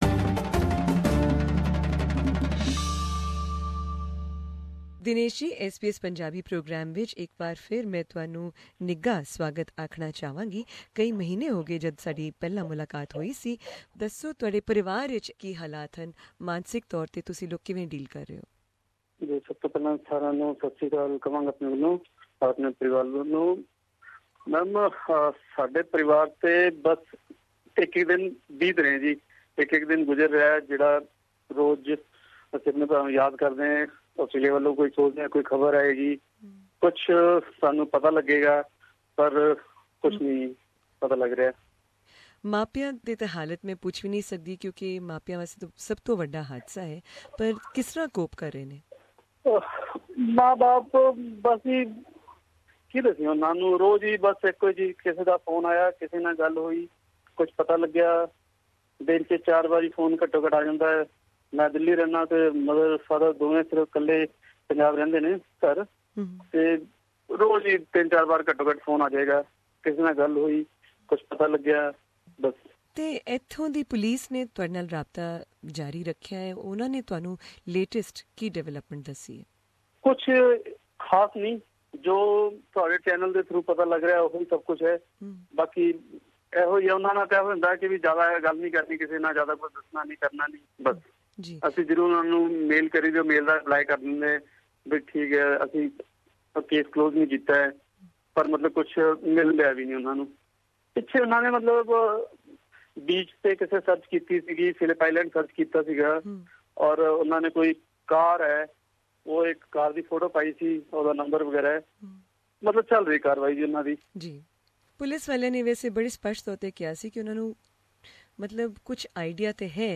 Here is the podcast of this interview, as broadcast on SBS Punjabi program on Thursday, March 5, 2015.